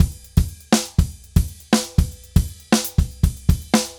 Drums_Baion 120_4.wav